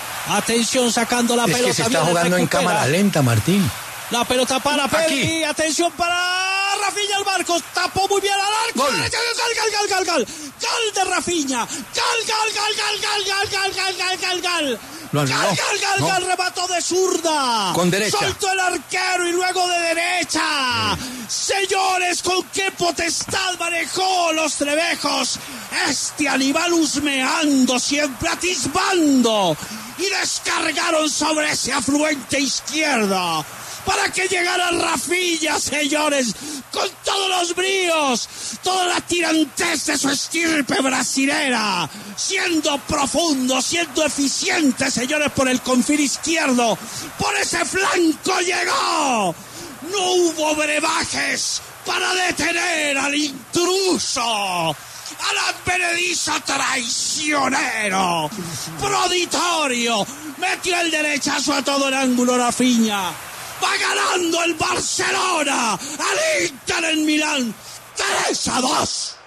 “Llegó con toda la tirantez de su estirpe brasilera”: Martín de Francisco narró el gol de Raphinha
“No hubo brebajes para detener a ese intruso, metió el derechazo a todo el ángulo Raphinha”, narró Martín de Francisco en transmisión especial junto a Hernán Peláez.
Así fue la narración de Martín de Francisco del gol del Barcelona: